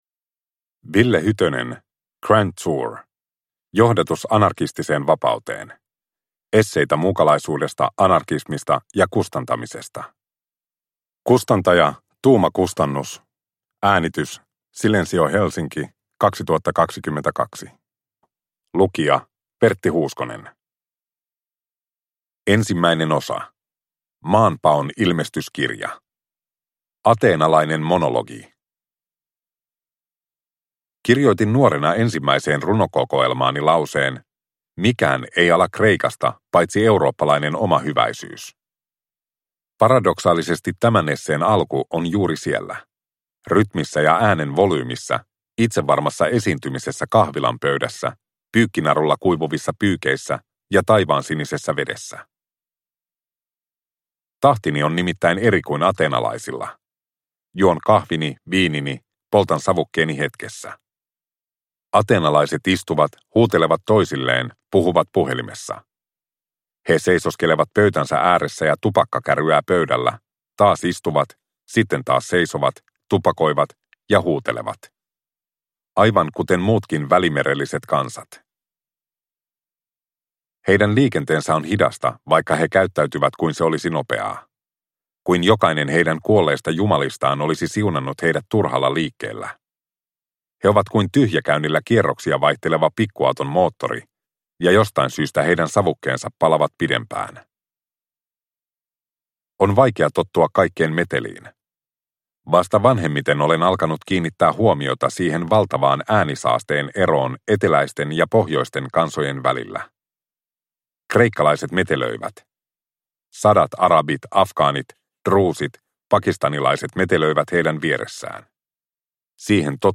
Grand Tour – Ljudbok